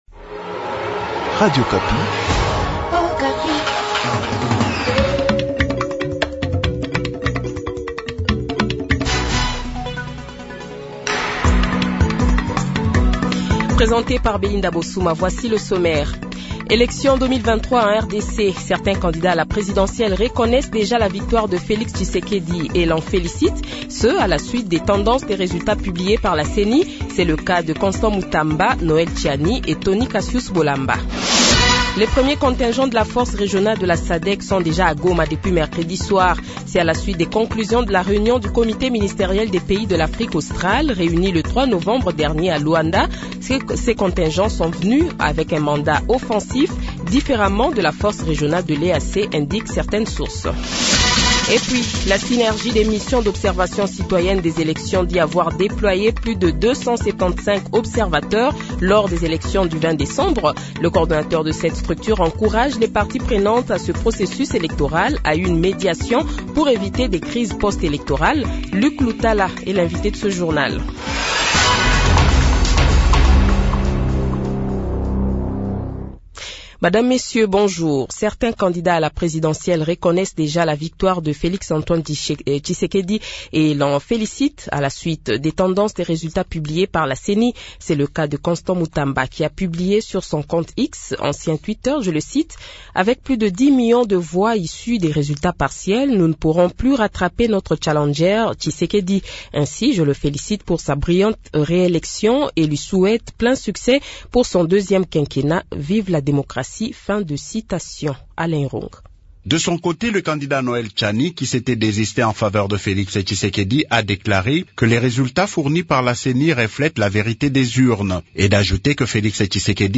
Le Journal de 12h, 29 Decembre 2023 :